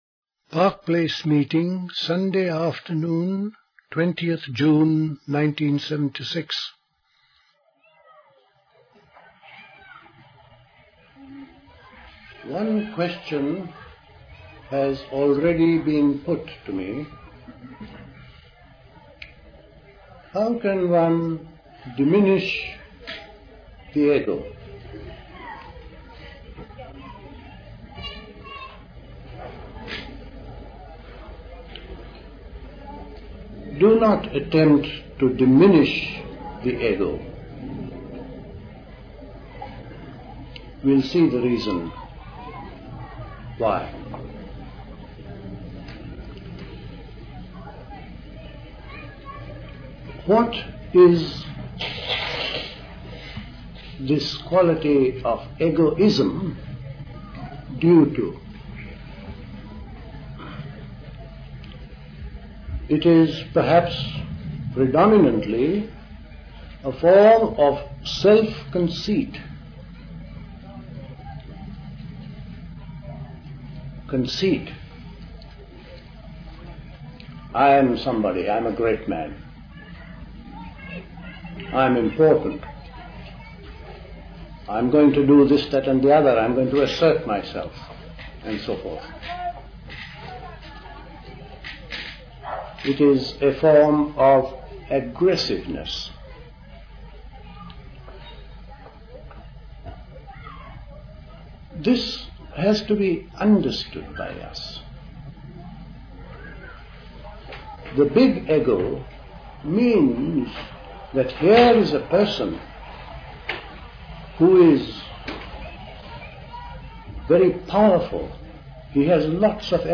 Recorded at the 1976 Park Place Summer School.